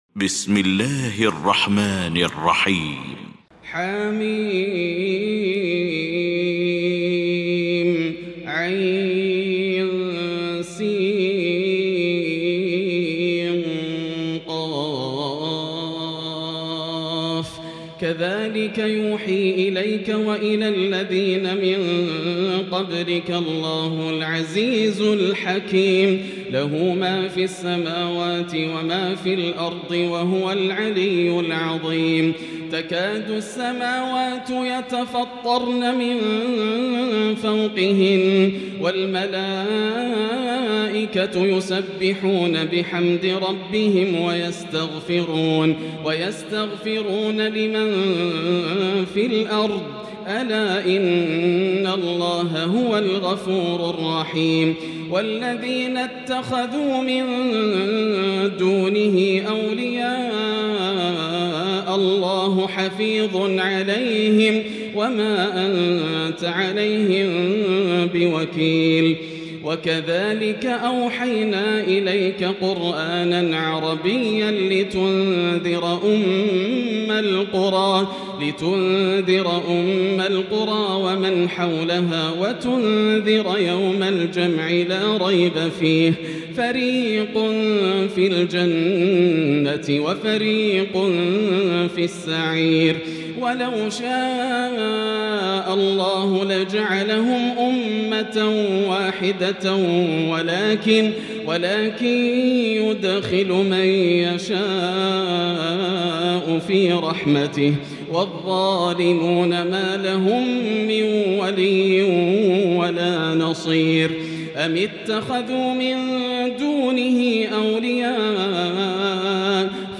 المكان: المسجد الحرام الشيخ: فضيلة الشيخ ياسر الدوسري فضيلة الشيخ ياسر الدوسري الشورى